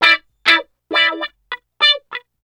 66 GUITAR -R.wav